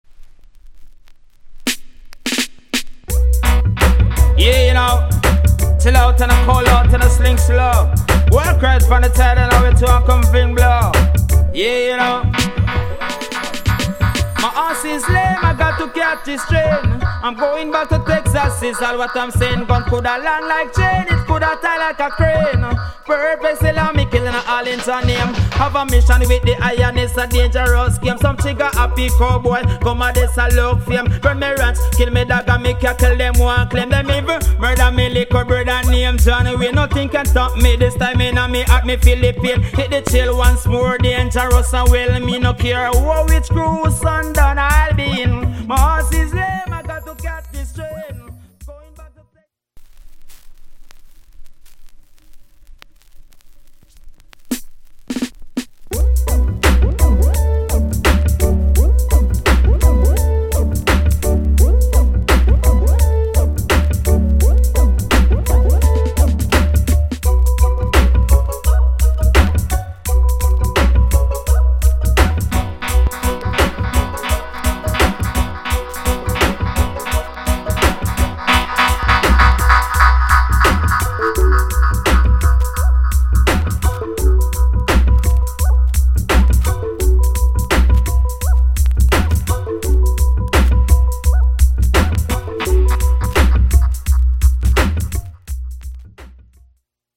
* '94 Jump Up trk